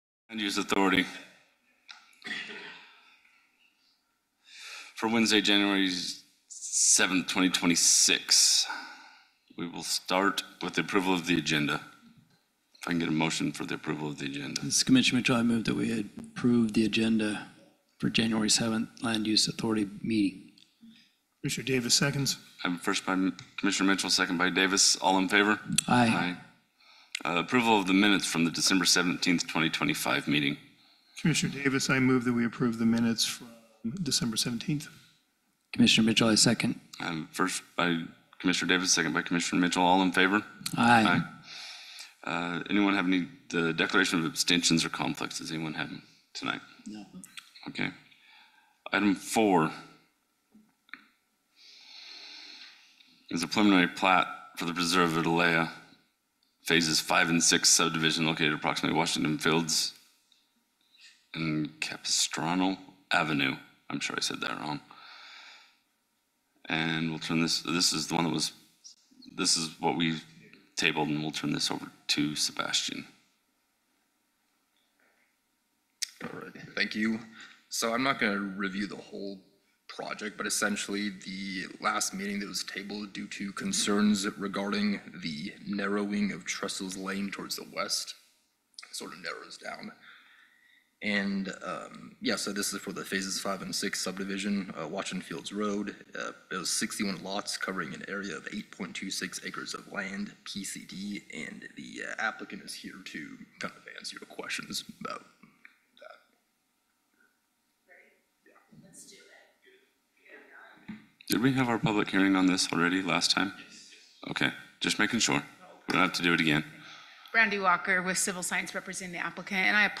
Regular Meeting Agenda